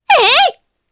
Laias-Vox_Attack4_jp.wav